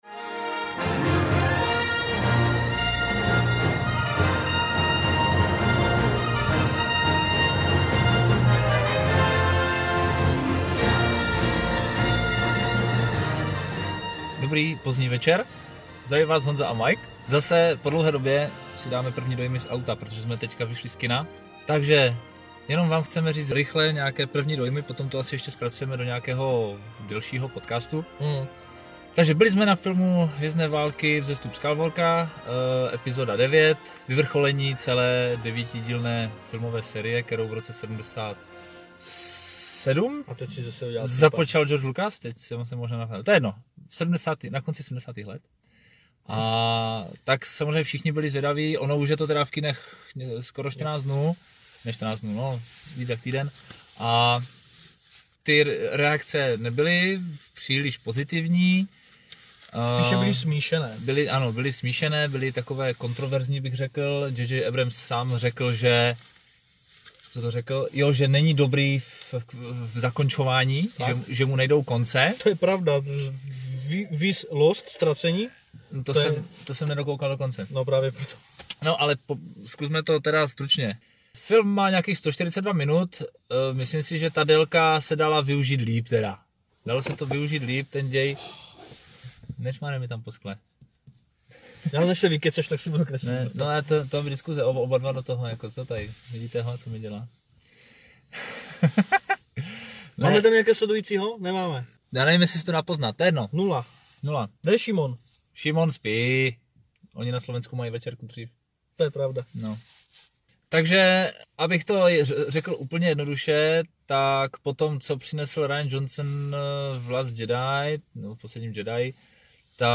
Před půlnocí jsme vyšli z kina v Ostravě a jako obvykle, jsem vypustili naše dojmy ven rovnou z auta. Že to šlo o zklamání asi není třeba předesílat.